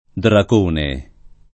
dragone [drag1ne] s. m. — ant. latinismo dracone [